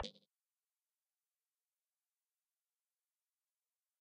rocket-2.ogg